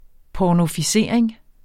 Udtale [ pɒnofiˈseˀɐ̯eŋ ]